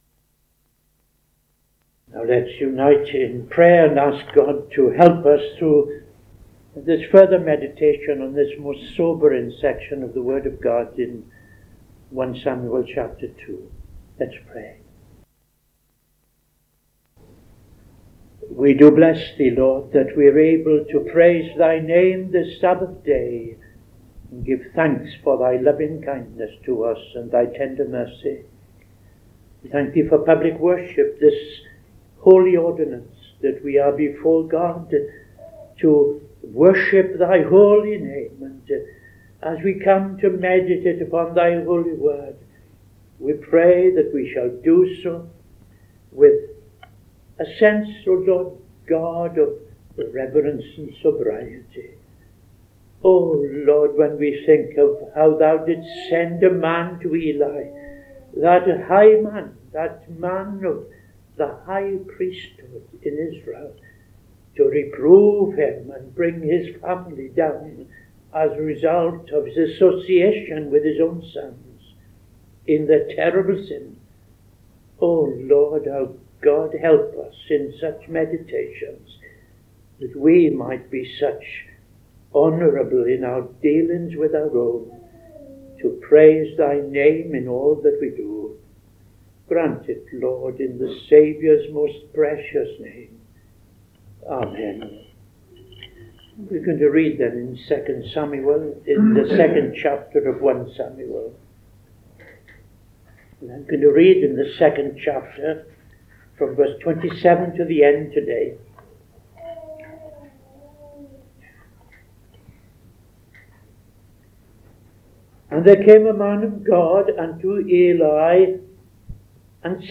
Bible Study 23rd September 2025